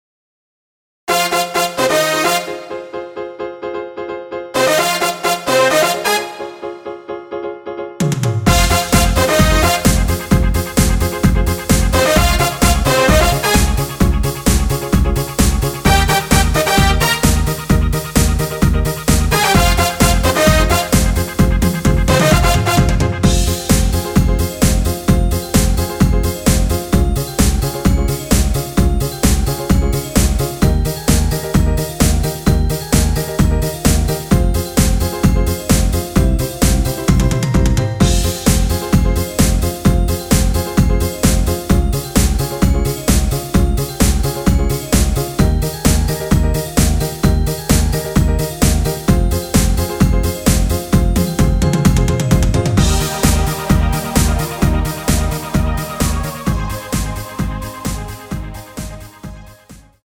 원곡 보다 템포를 빠르게 제작하고
엔딩이 페이드 아웃이라 엔딩을 만들어서 편곡한 MR입니다.(엔딩부분은 멜로디 MR 미리듣기 확인)
원키 편곡 MR입니다.(미리듣기 확인)
Fm
앞부분30초, 뒷부분30초씩 편집해서 올려 드리고 있습니다.